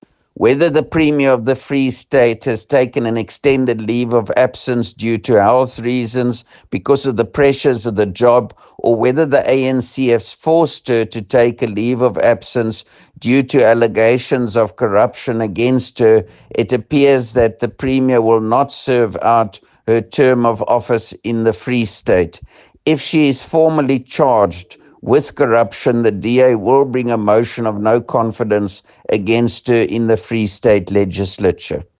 Afrikaans soundbites by Roy Jankielsohn MPL and Sesotho soundbite by Jafta Mokoena MPL